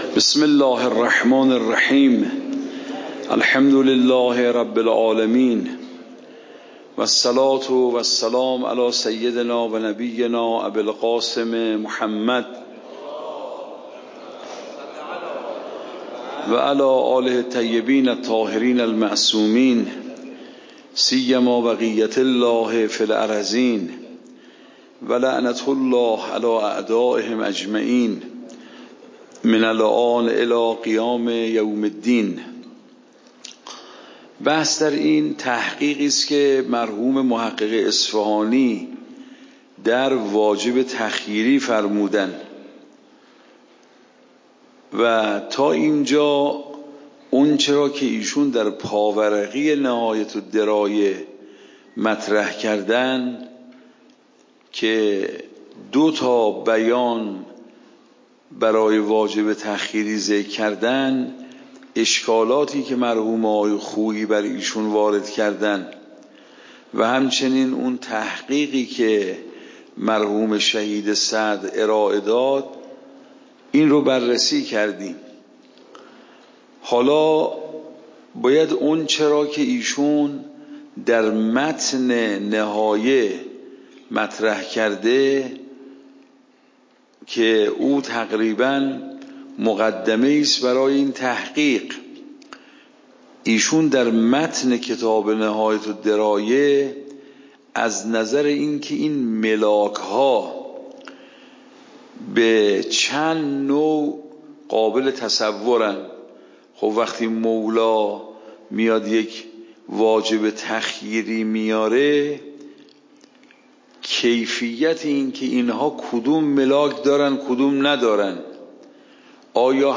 درس بعد واجب تعییینی و تخییری واجب تعییینی و تخییری درس بعد موضوع: واجب تعیینی و تخییری اصول فقه خارج اصول (دوره دوم) اوامر واجب تعیینی و تخییری تاریخ جلسه : ۱۴۰۴/۸/۶ شماره جلسه : ۲۸ PDF درس صوت درس ۰ ۱۶۲